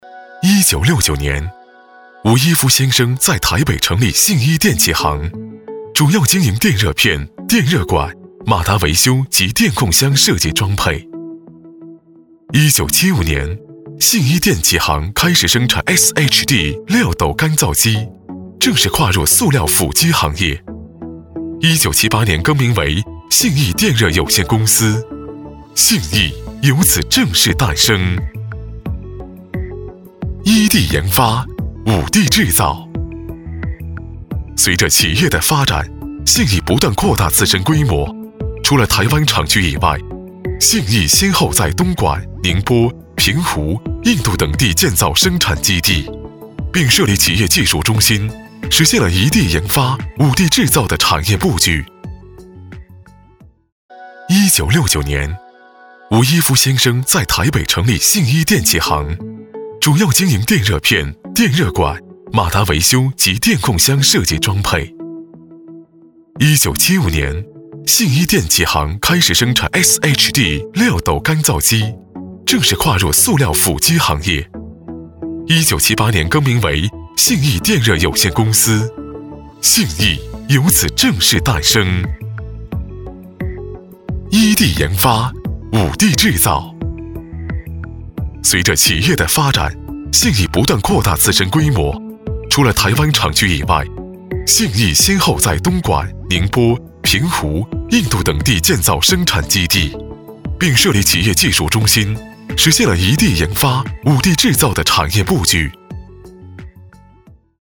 国语青年大气浑厚磁性 、沉稳 、科技感 、男专题片 、宣传片 、200元/分钟男S355 国语 男声 宣传片-高洲酒业-产品解说-大气浑厚 大气浑厚磁性|沉稳|科技感